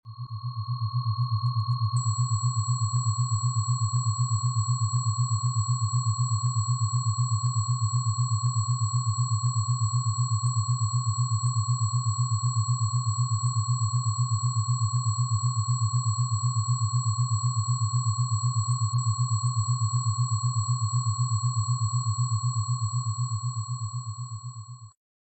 Tinnitus Relief Frequency – Naturally sound effects free download
Calm & Soothe Your Ears in Just 5 Minutes